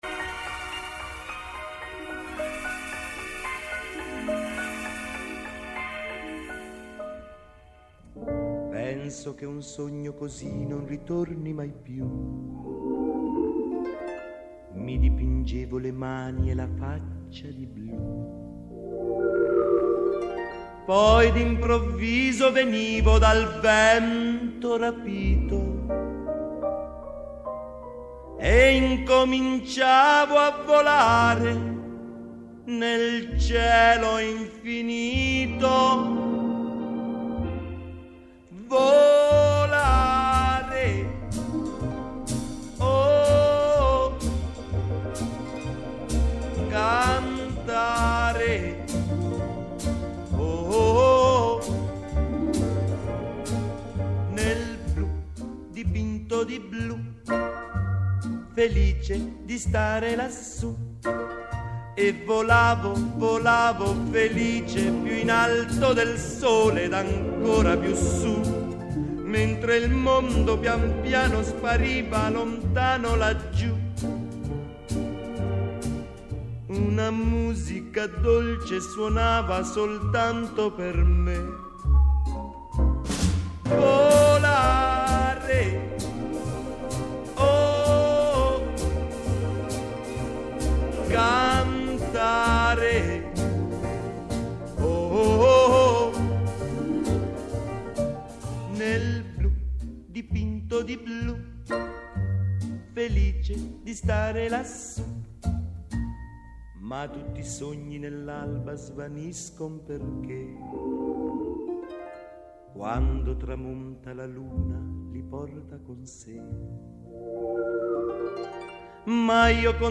Αφιέρωμα στα Βραβεία Grammy: Στο στούντιο